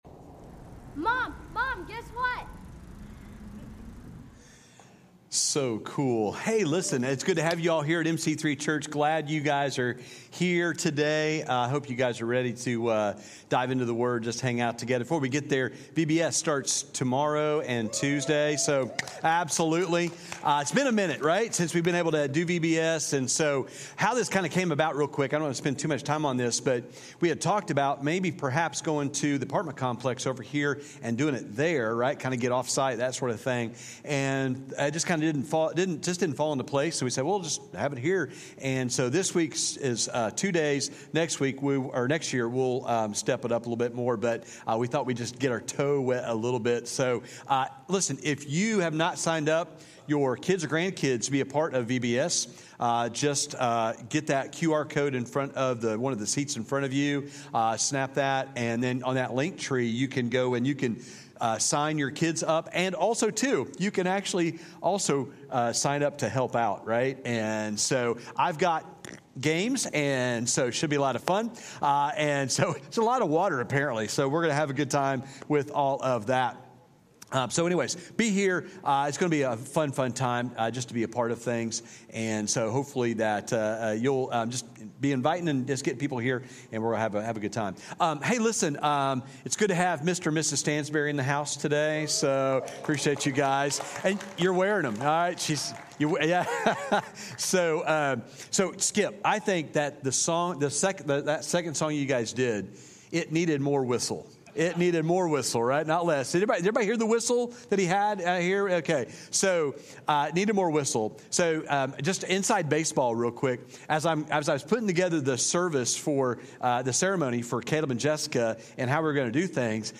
6-9-24-sermon-audio-only.mp3